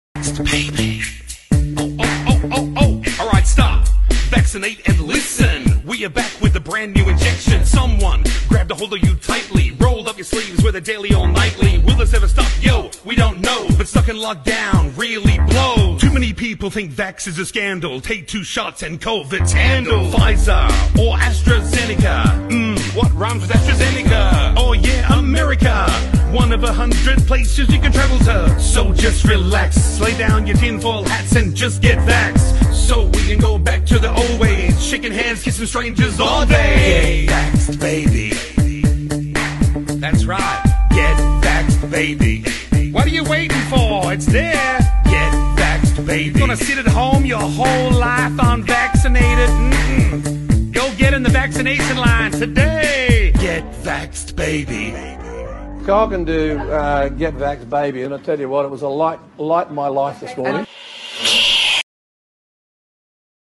He has a good flow... sadly on the wrong side of the wall...
Music